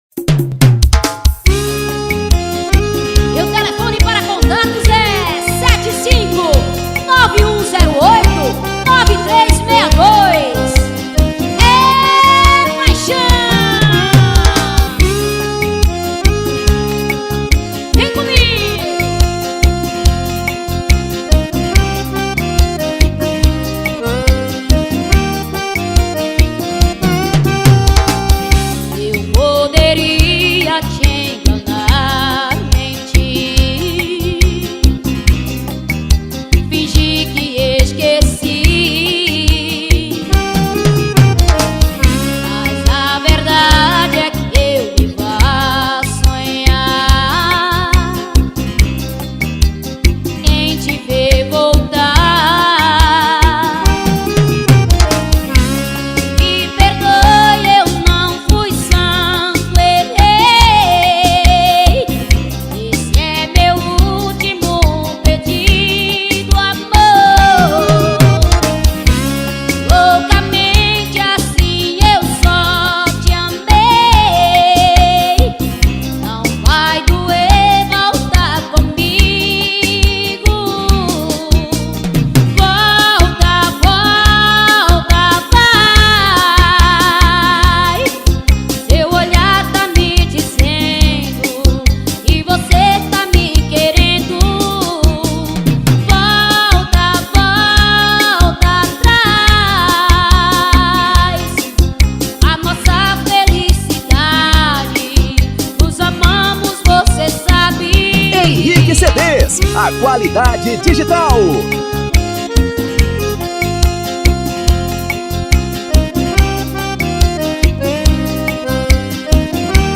2025-03-14 01:43:36 Gênero: Forró Views